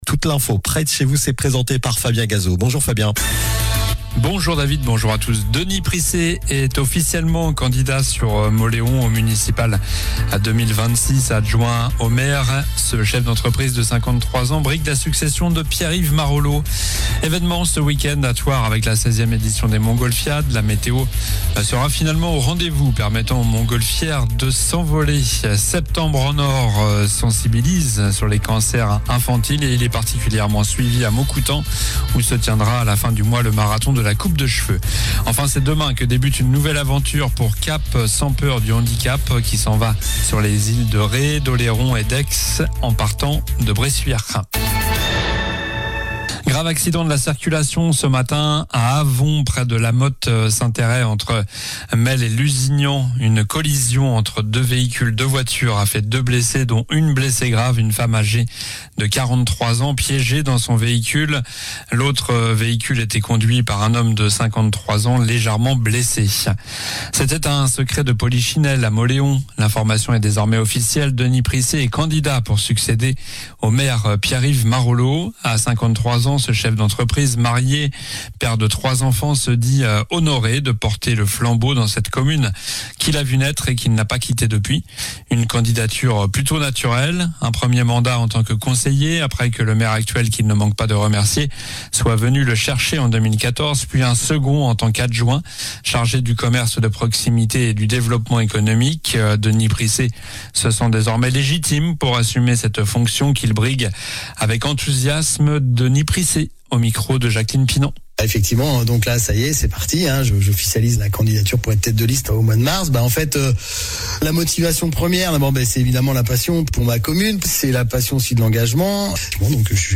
Journal du vendredi 05 septembre (midi)